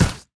etfx_explosion_sharp.wav